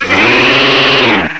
sovereignx/sound/direct_sound_samples/cries/gogoat.aif at master